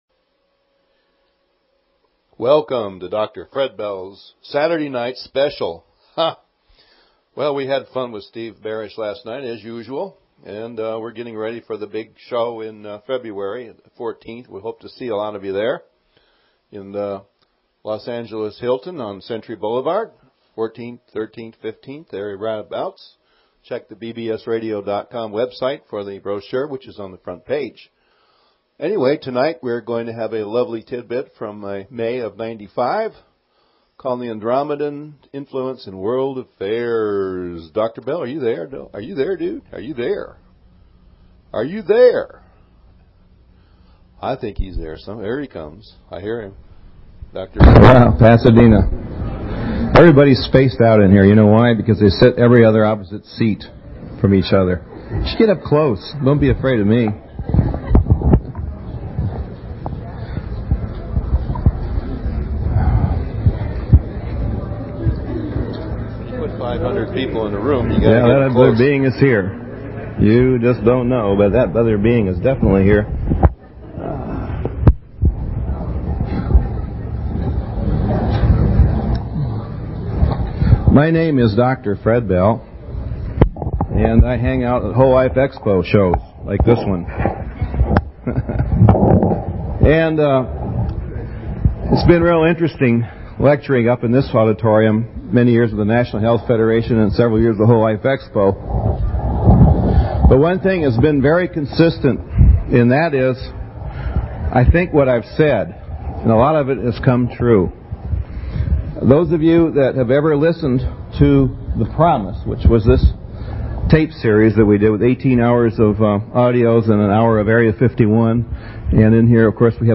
Talk Show Episode
His seminar on the Andromedian influence on World events recorded in 1995 but is certainly cutting edge info for us to know today in 2009 as at this point we can see how much of what they said has been shown to be true!